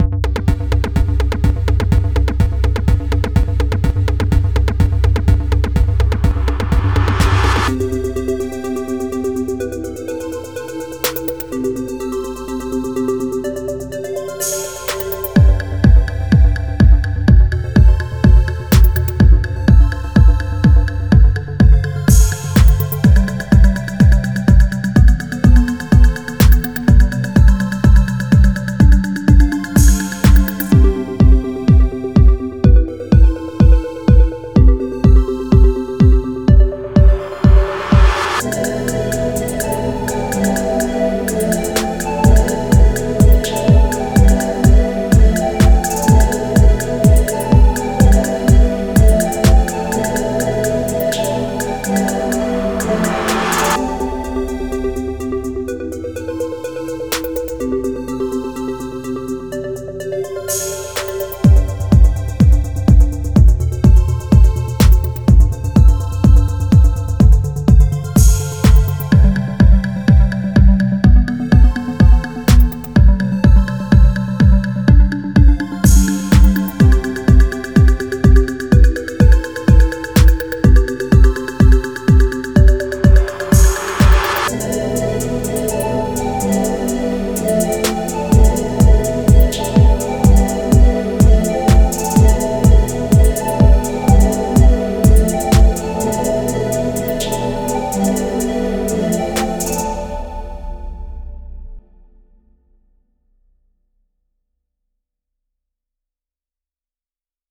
Time – (1:48)　bpm.125